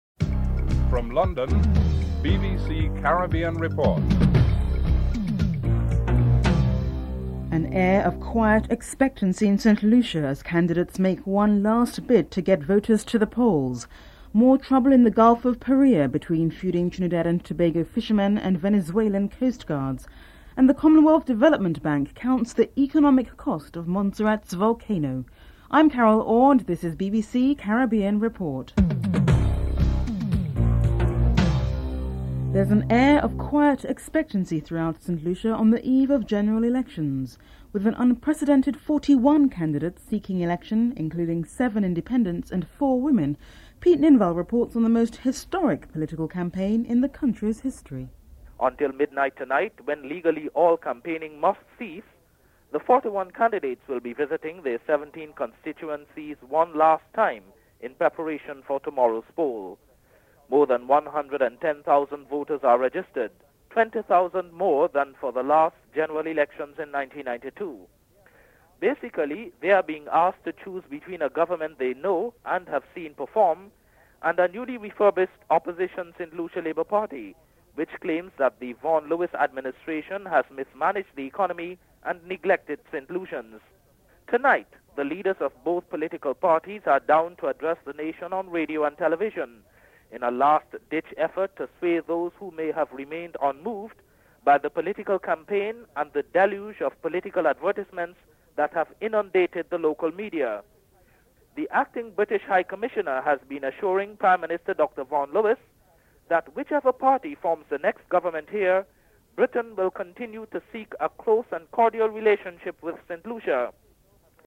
1. Headlines (00:00-00:31)
West Indies cricketers, Ian Bishop and Brian Lara are interviewed (12:40-15:24)